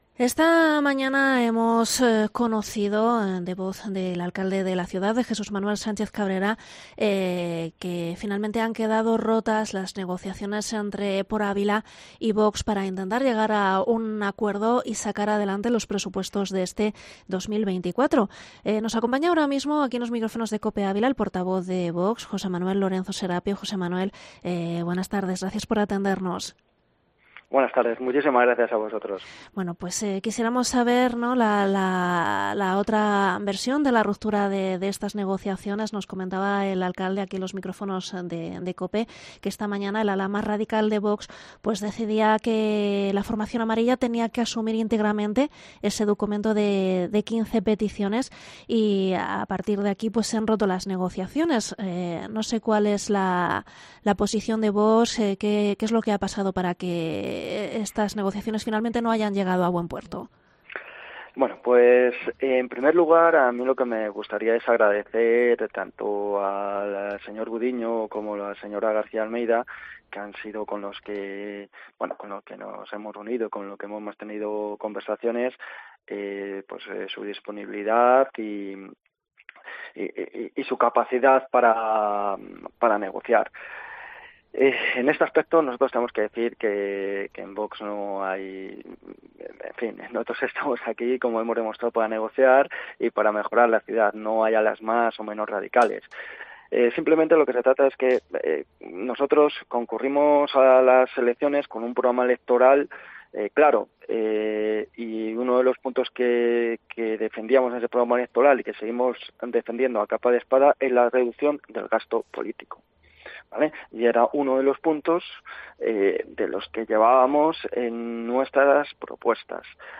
ENTREVISTA al portavoz de Vox, José Manuel Lorenzo Serapio